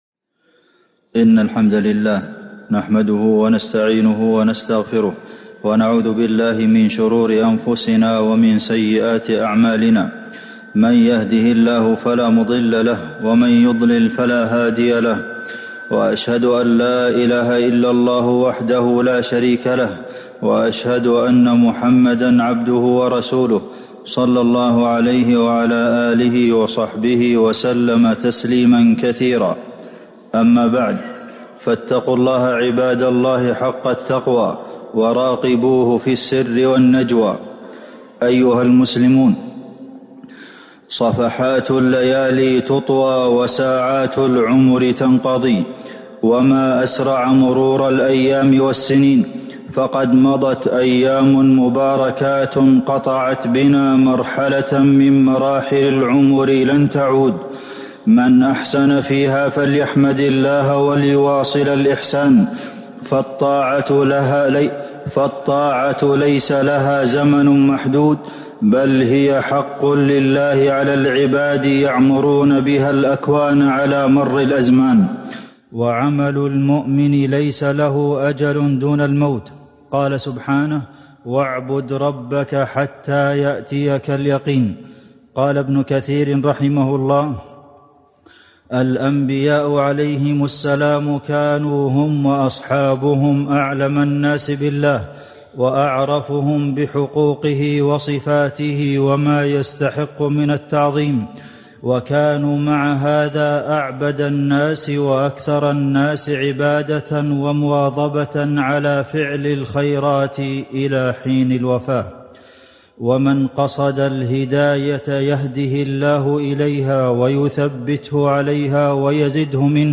خطبة الجمعة _ المداومة على الطاعة بعد رمضان - الشيخ عبد المحسن القاسم